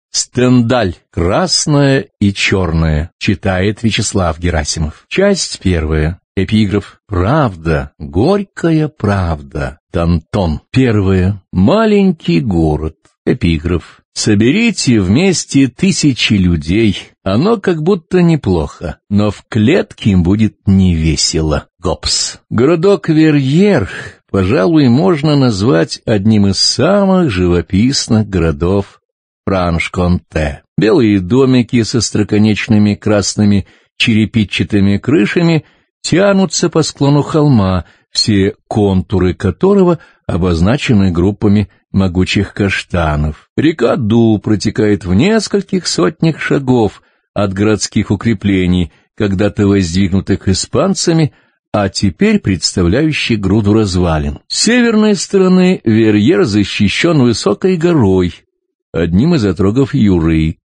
Аудиокнига Красное и черное | Библиотека аудиокниг